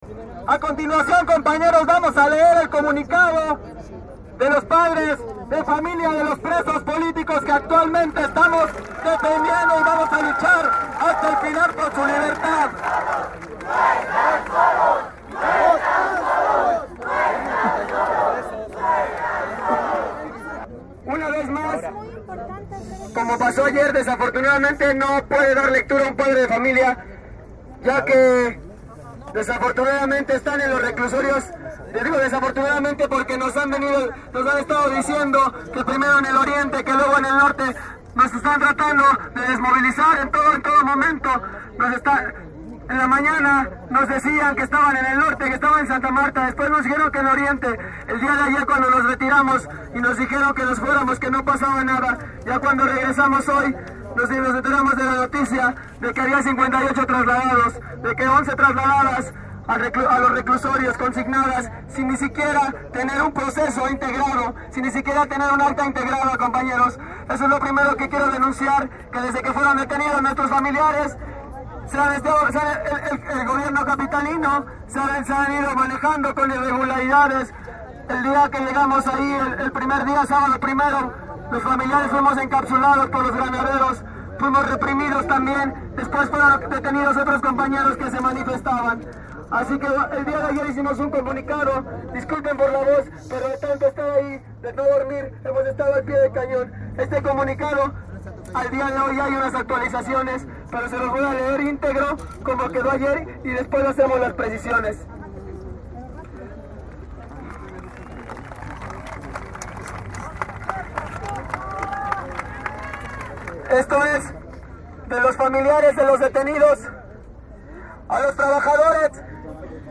Durante el mitin en el zócalo se vivía un ambiente de tranquilidad, silencio y atención a las palabras emitidas a través del megáfono.Los presentes escuchaban con atención sentados desde sus lugares en la plancha del Zócalo.